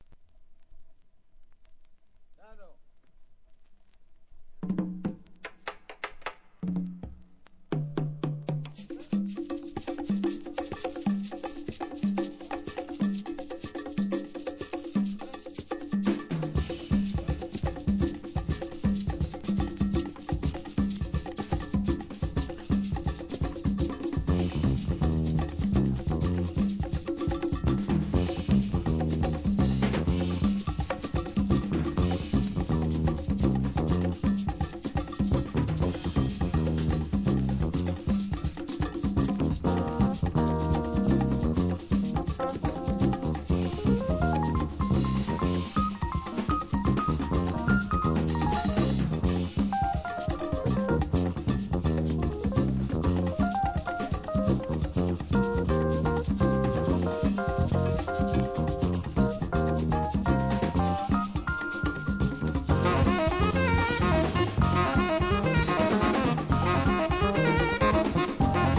店舗 ただいま品切れ中です お気に入りに追加 1977年、スピリチュアル・ラテン・ジャズ!!